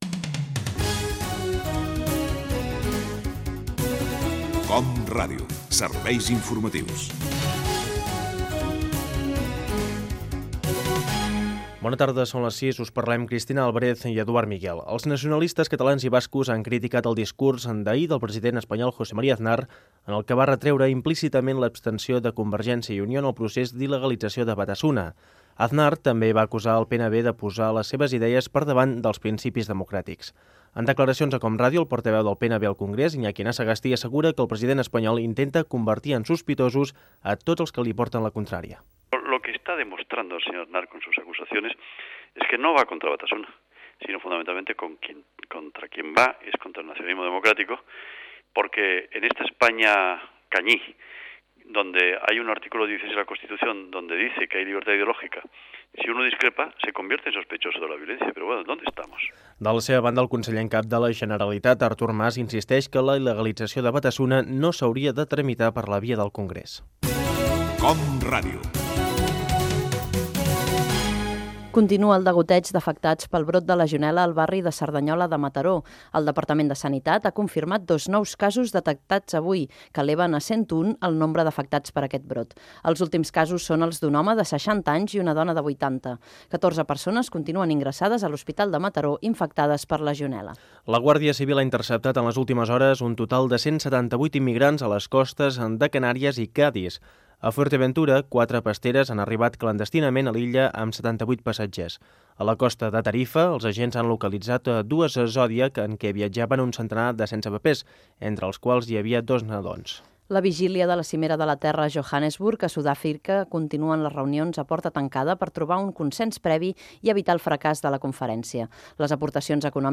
Careta del programa. Informació sobre la possible il·l3gatizació de Barasuna, casos de legionel·la a Mataró, pasteres que arriben a Andalusia, Cimera de la Terra a Sud-àfrica, etc. Esports
Informatiu